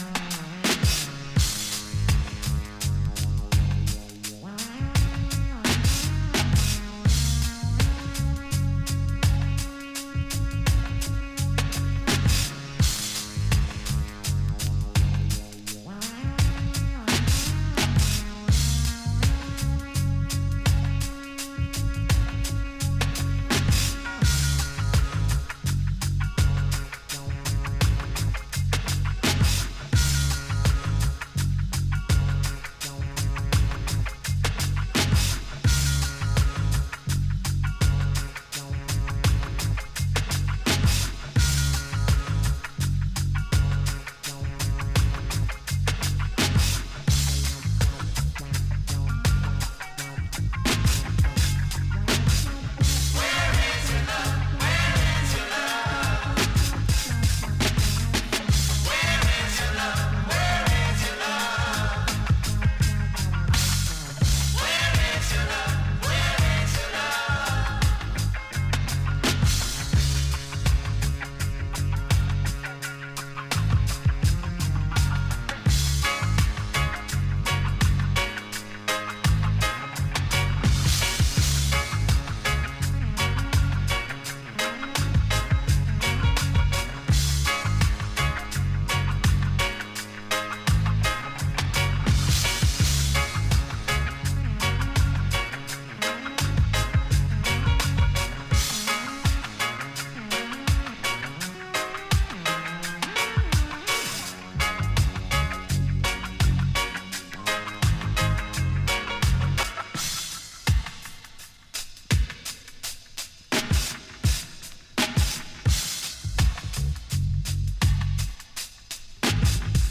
ここでは、シンセ・ポップやアフロ・トロピカル路線の音源をネタに用いながらゆるくてダビーなバレアリック・トラックを展開。